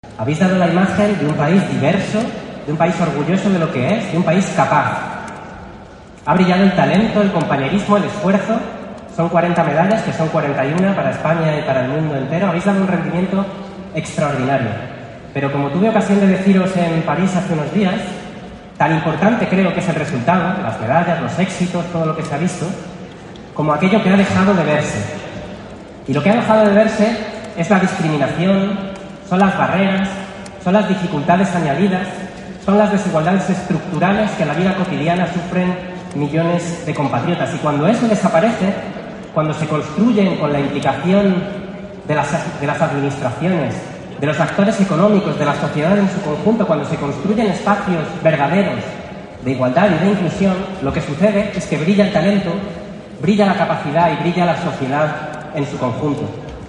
Foto de familia del acto de acogida en Madrid del equipo paralímpicoLa ministra de Educación, Formación Profesional y Deportes, Pilar Alegría, junto al ministro de Derechos Sociales, Consumo y Agenda 2030, Pablo Bustinduy, homenajearon el 10 de seprtiembre en Madrid al Equipo Paralímpico Español, tras el éxito logrado en los Juegos de París 2024 en los que ha logrado un total de 40 medallas.